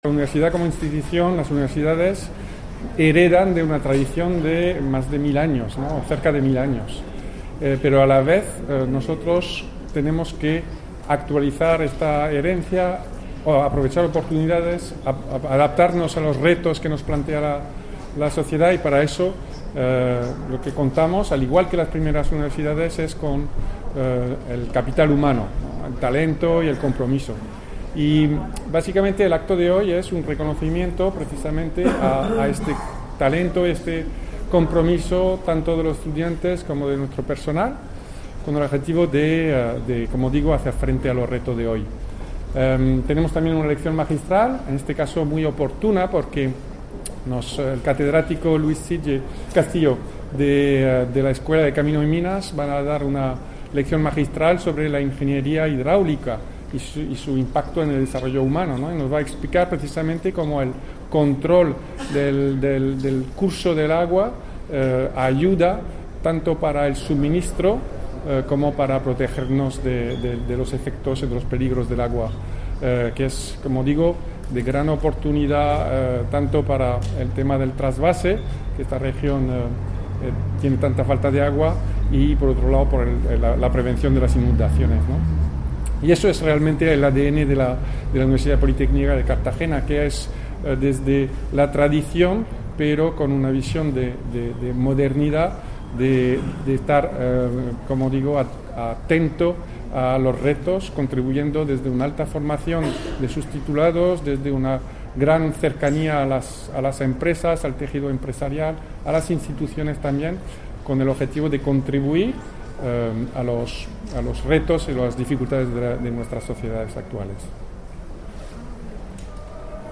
Enlace a Acto académico en la UPCT por la fesetividad de Santo Tomás de Aquino